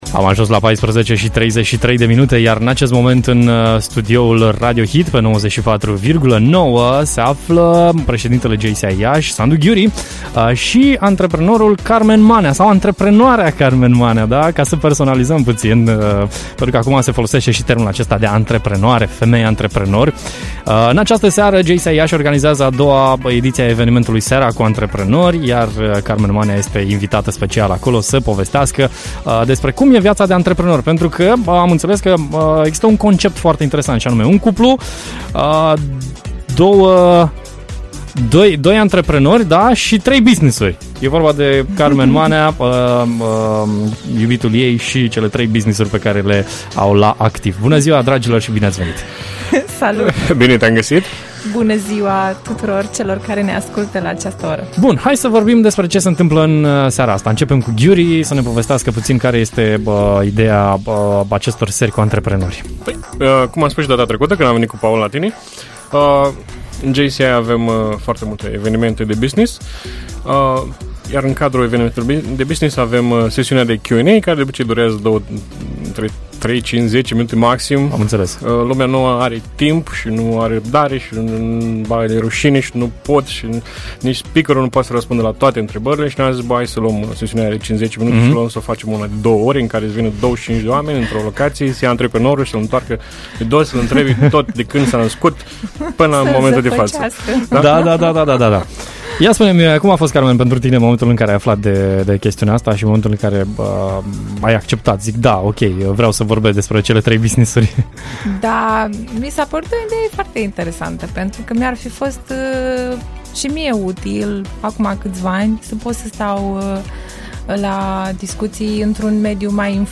Înainte de eveniment ea a fost live în studioul Radio Hit